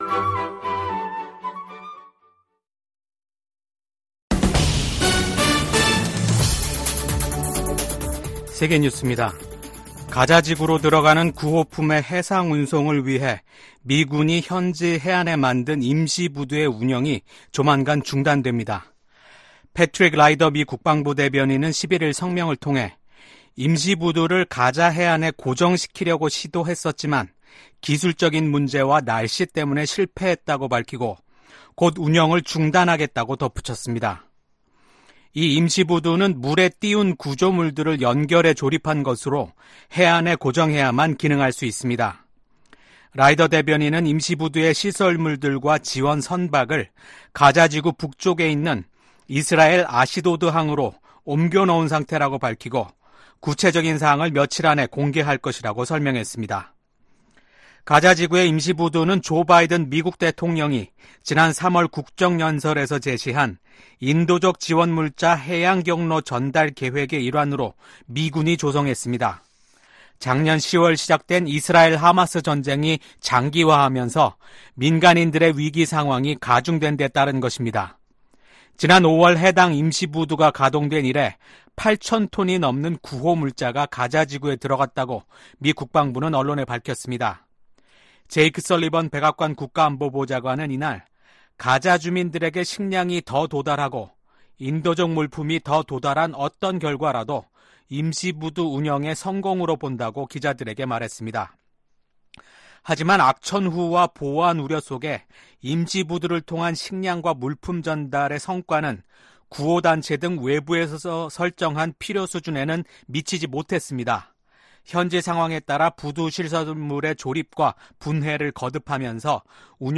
VOA 한국어 아침 뉴스 프로그램 '워싱턴 뉴스 광장' 2024년 7월 13일 방송입니다. 조바이든 미국 대통령과 윤석렬 한국 대통령은 워싱턴에서 북한의 한국 핵 공격 시, 즉각적이고 압도적이며 결정적인 대응을 한다는 공동성명을 발표했습니다.